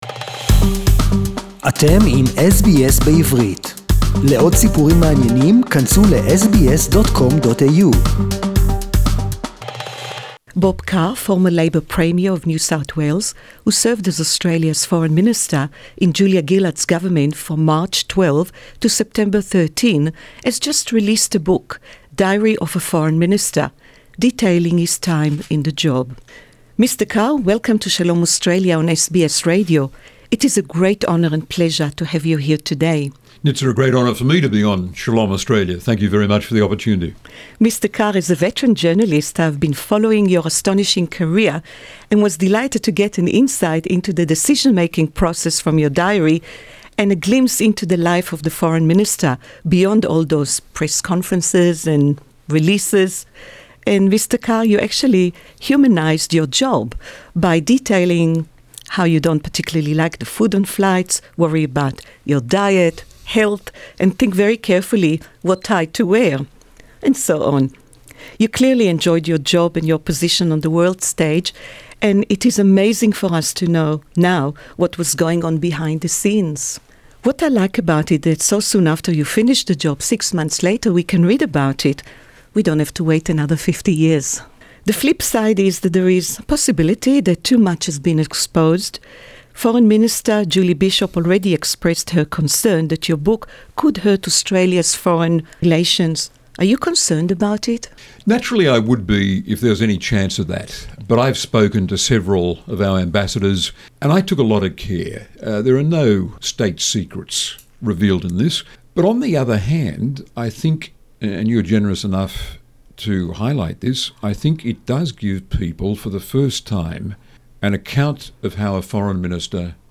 Replay of 2014 Interview